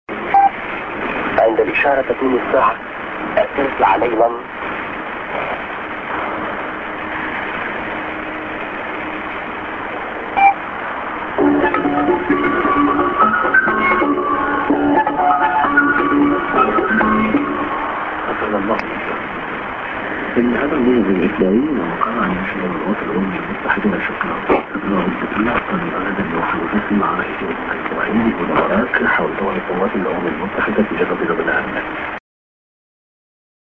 Time check->ANN(men)->Time check->SJ->ANN(man)